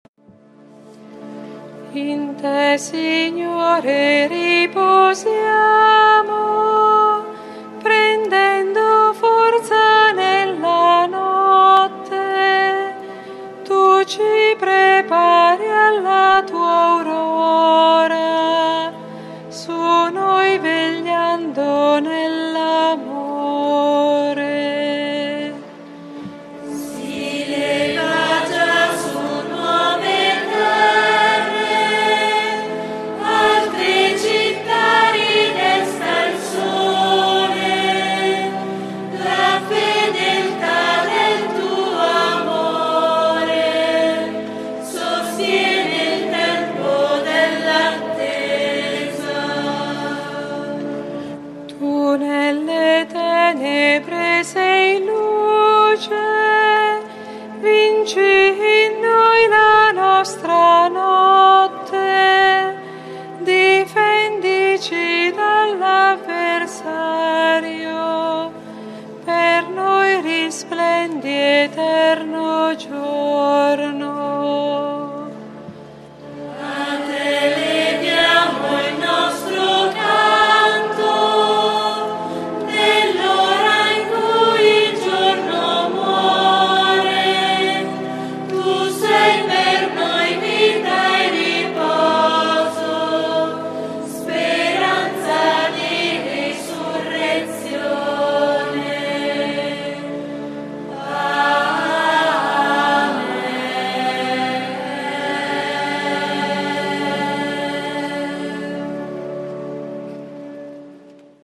GIOVEDI SANTO -Celebrazione della Cena del Signore